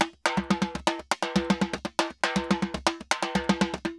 Timba_Salsa 120_1.wav